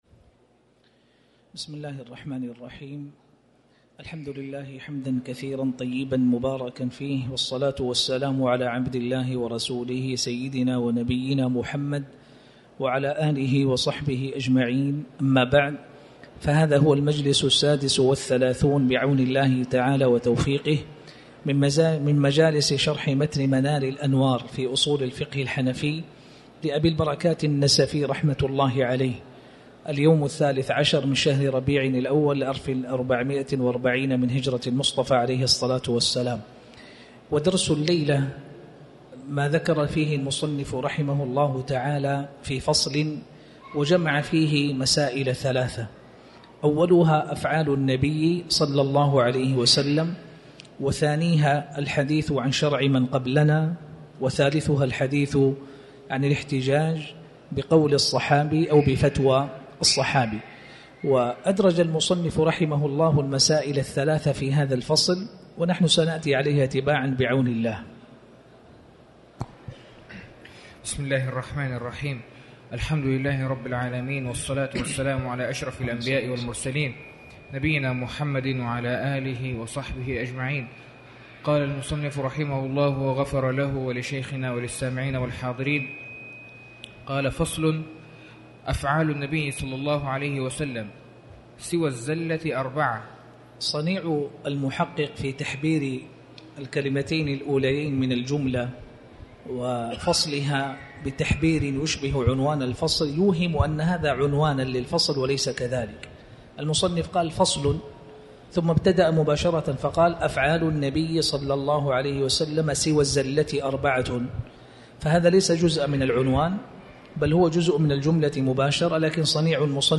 تاريخ النشر ١٣ ربيع الأول ١٤٤٠ هـ المكان: المسجد الحرام الشيخ